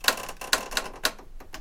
嘎吱声 " 木地板嘎吱声 08
描述：木制地板上的吱吱声录制在短片上。
使用索尼PCMD50。
标签： 地板 木材 吱吱
声道立体声